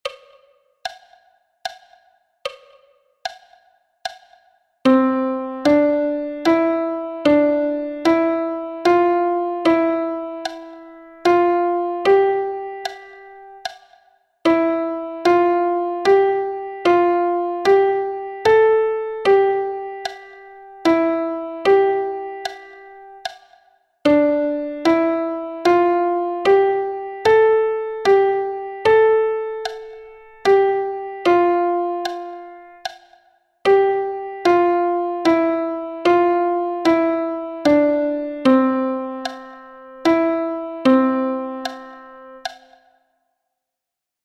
The first exercise has got a metronome sound to help you be accurate with measuring the time and mark the subdivision of each beat into three  (compound triple) .
1_melodia_6x8.mp3